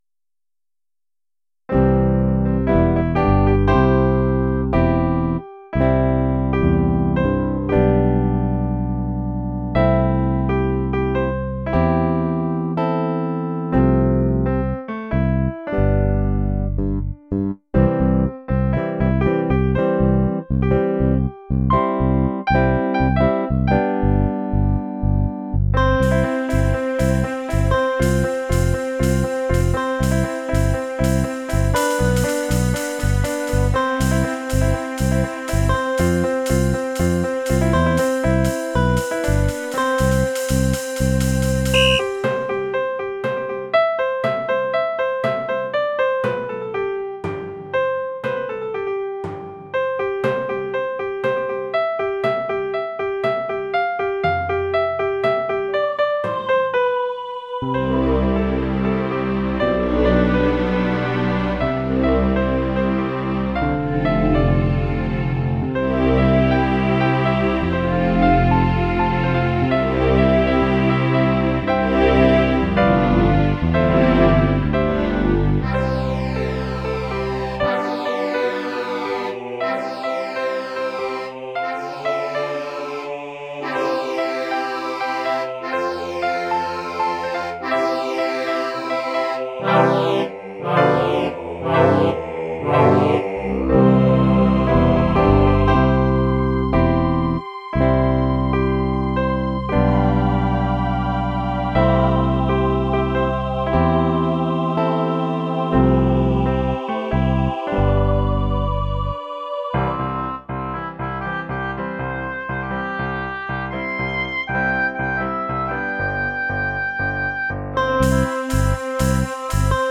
Instrumentierung/Stimmen: Klavier, Ah-Chor, FingrdBass Guit, Oboe, strings, Akkordeon, Schlagzeug, Klavier
Anfang als langsames, kirchenhymnenartiges Motiv, nur Bassgitarre und Klavier.